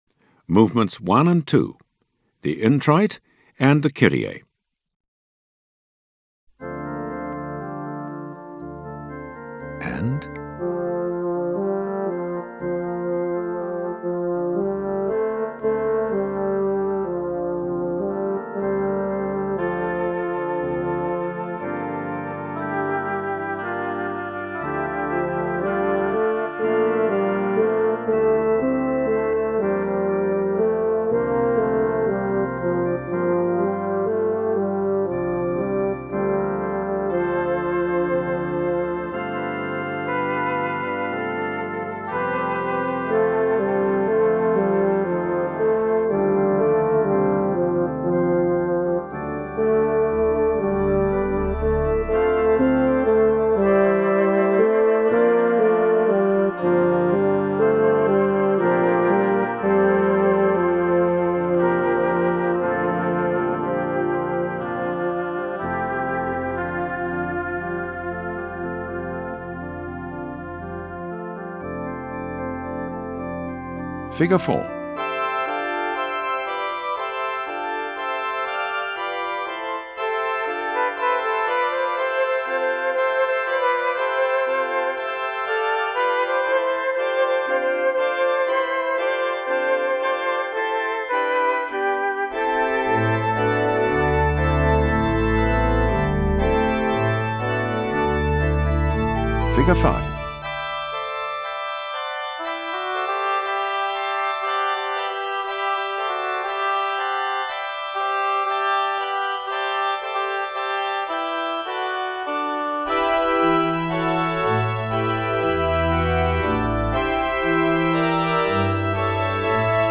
There is no text, just your part.
Introit- (2nd Bass)
05-Introit-2nd-Bass.mp3